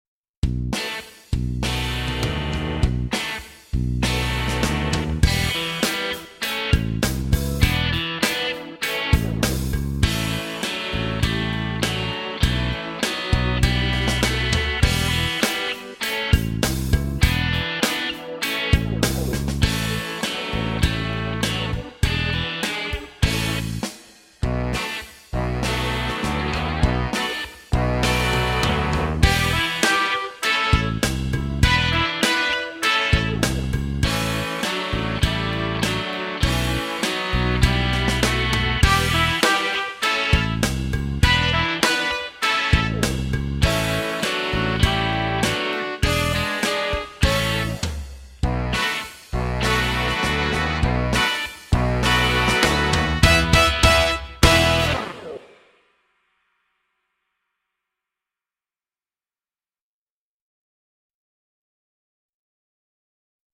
VS Violin Star (backing track)